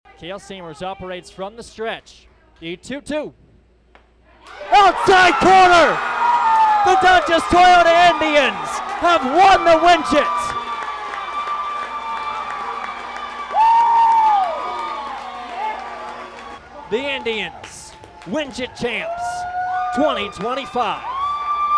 Here's how the final call sounded on KWON.